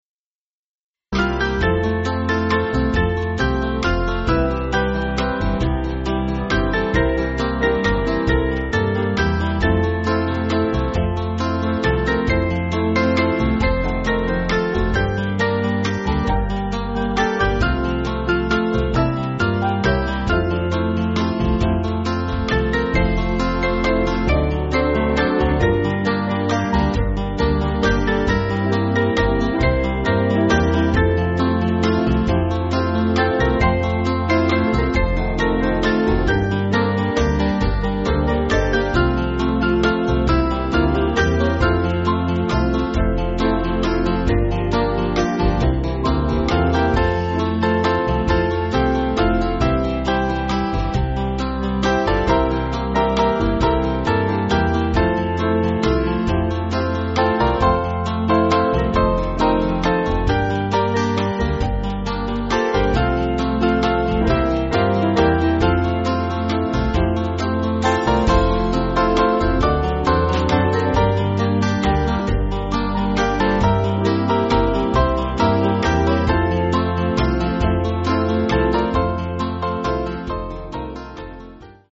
Small Band
(CM)   4/F-Gb